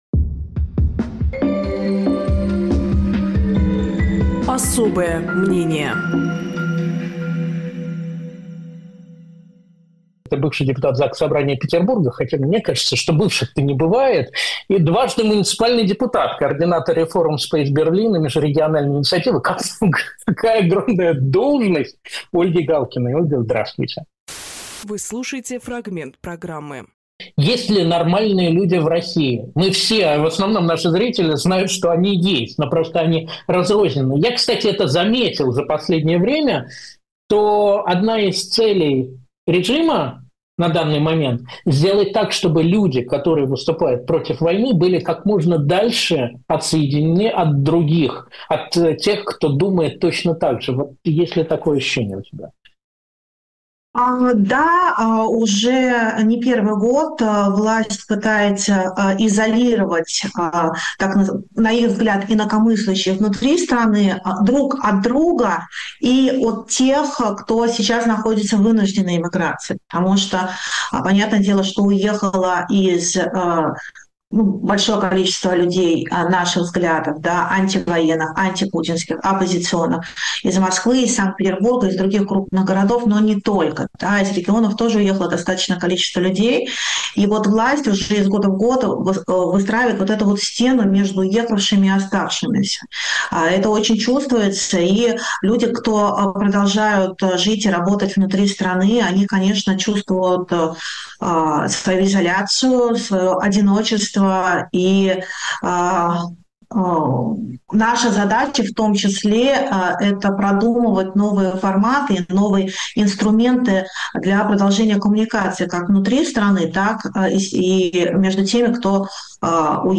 Фрагмент эфира от 20.01.26
Ольга Галкина политик
журналист